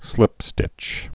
(slĭpstĭch)